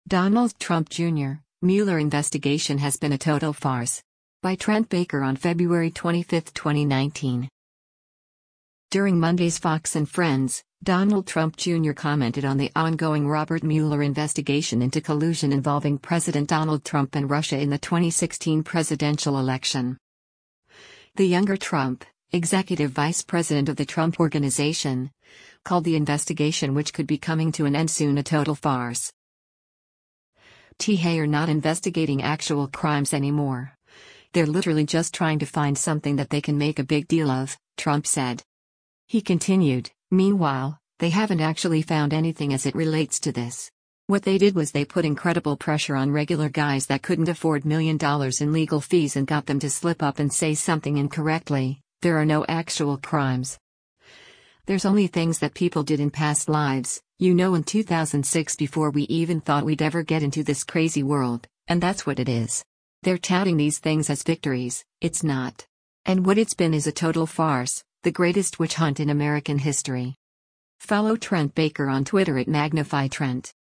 During Monday’s “Fox & Friends,” Donald Trump, Jr. commented on the ongoing Robert Mueller investigation into collusion involving President Donald Trump and Russia in the 2016 presidential election.